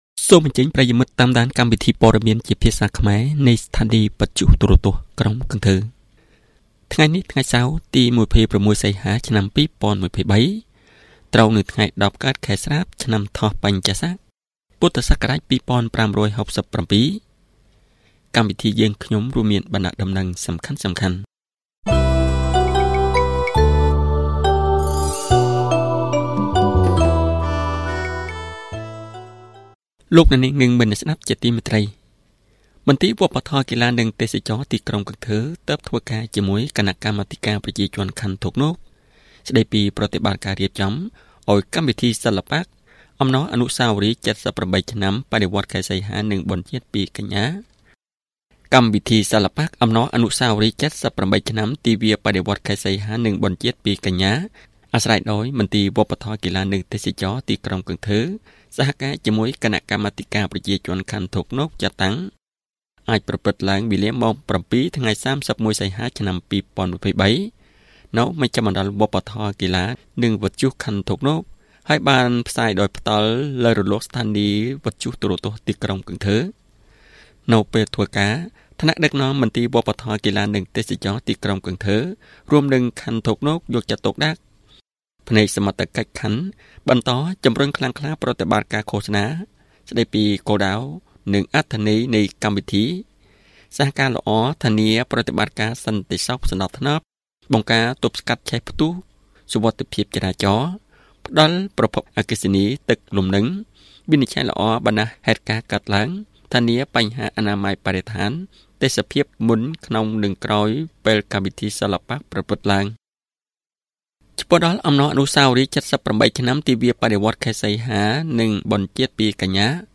Bản tin tiếng Khmer sáng 26/8/2023